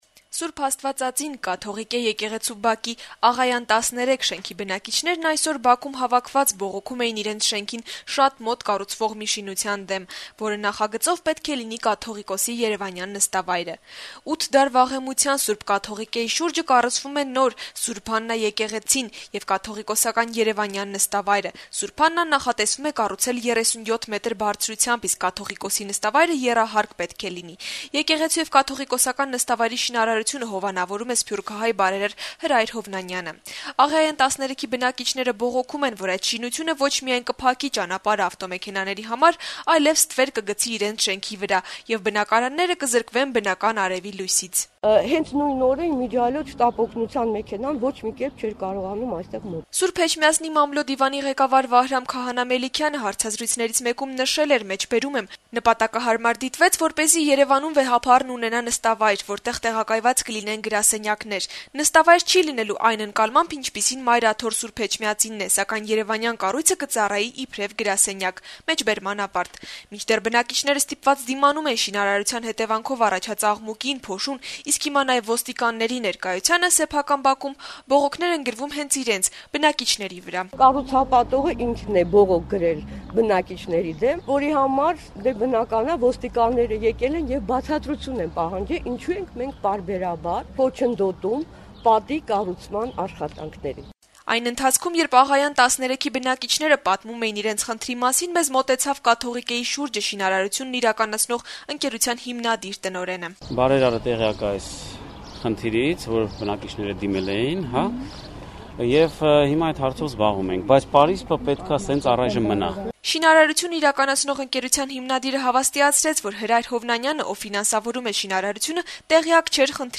«Ազատություն» ռադիոկայանի հետ զրույցում Աղայան 13 շենքի բնակիչները դժգոհեցին, որ նախատեսվող շինությունները ոչ միայն կփակեն ճանապարհը ավտոմեքենաների համար, այլեւ կզրկեն իրենց բնակարանները արեւի բնական լույսից։ Պատմեցին, որ շինարարության նպատակով տեղադրված ժամանակավոր պարսպի պատճառով օրերս կանչված շտապօգնության մեքենան չի կարողացել շենքին մոտենալ։